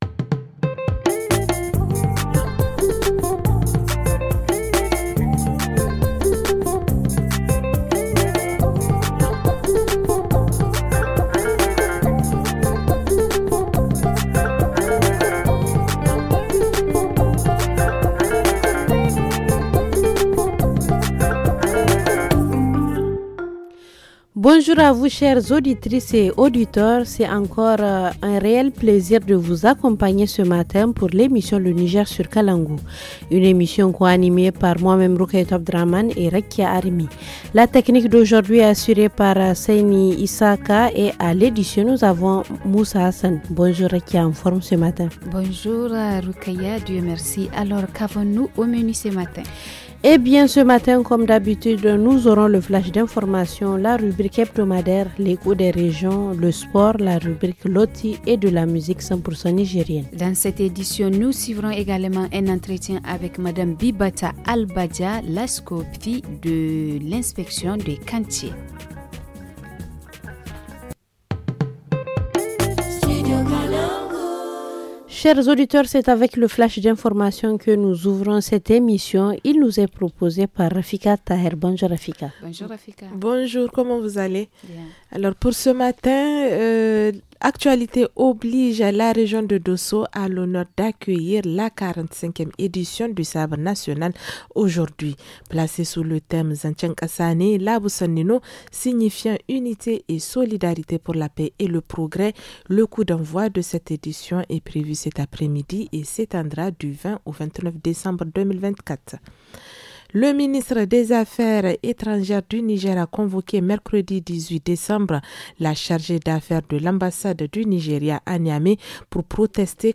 entretien du jour